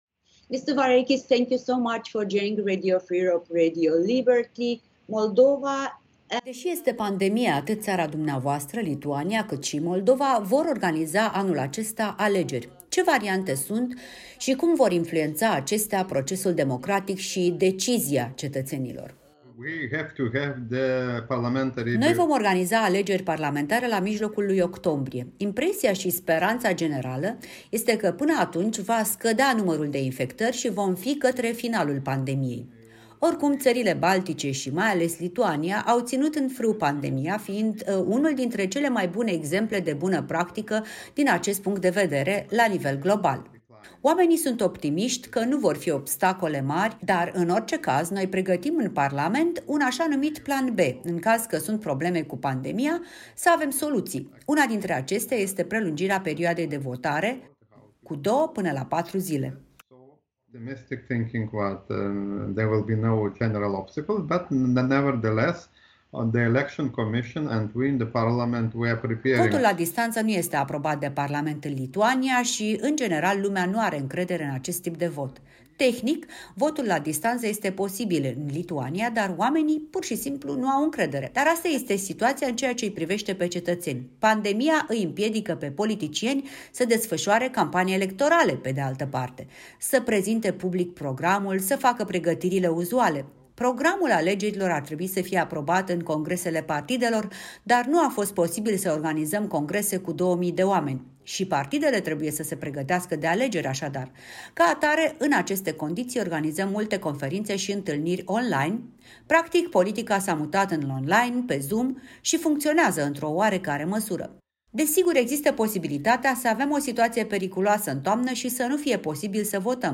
Un interviu cu deputatul lituanian, co-raportor APCE pentru Moldova